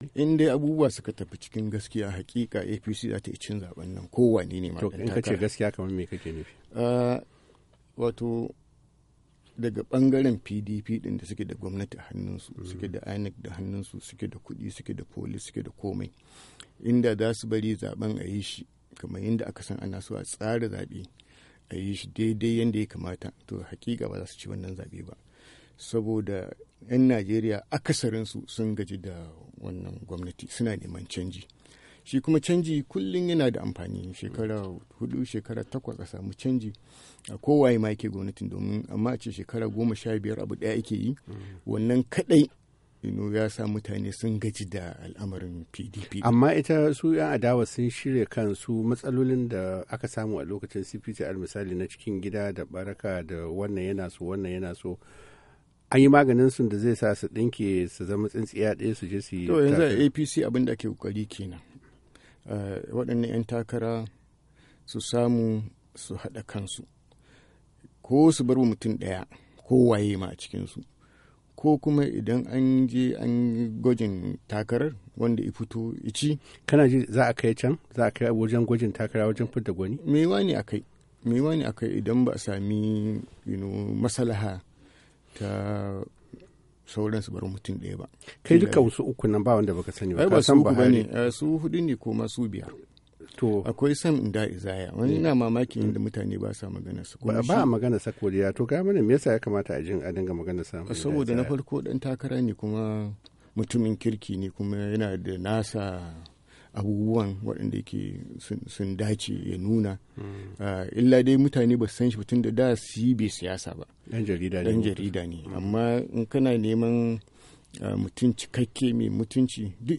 Alhaji Bashir Tofa wanda ya tsaya takarar neman shugaban kasar Najeriya a shekarar 1992 yayi furuci akan zaben 2015 cikin wata fira da yayi da Muryar Amurka.